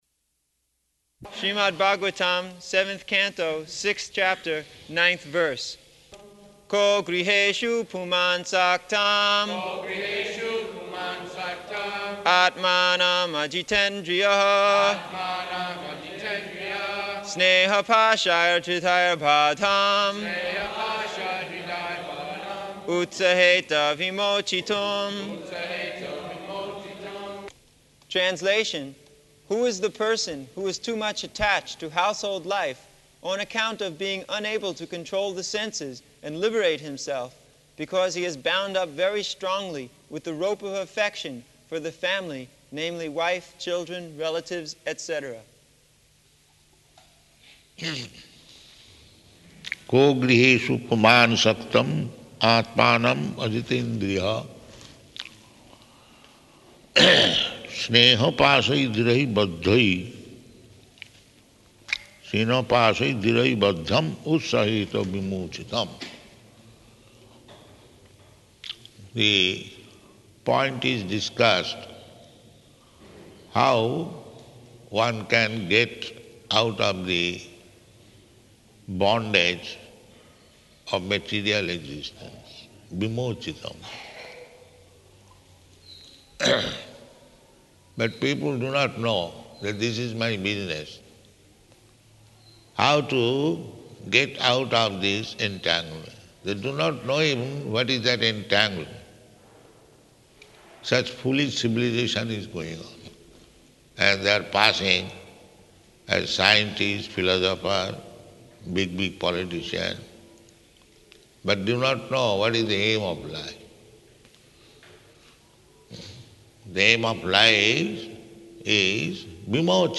December 11th 1975 Location: Vṛndāvana Audio file
[leads chanting of verse, etc.]